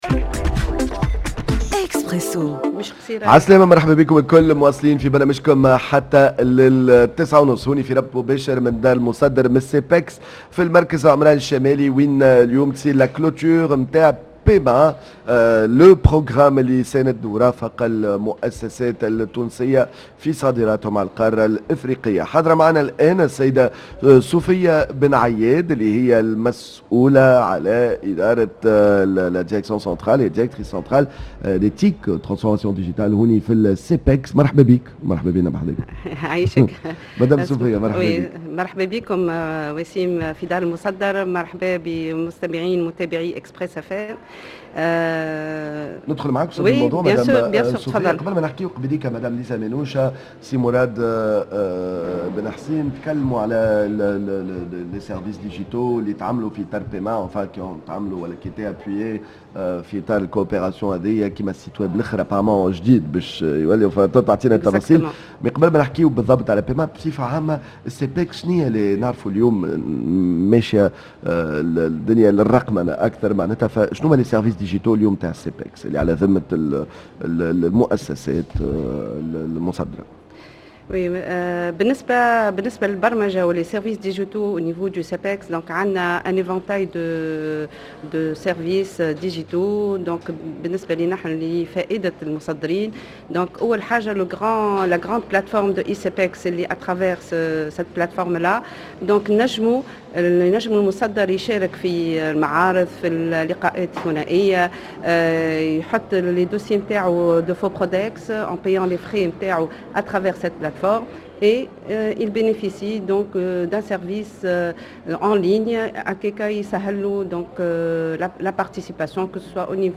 dans un plateau spécial en direct du Tunisia Export – Centre de Promotion des Exportations CEPEX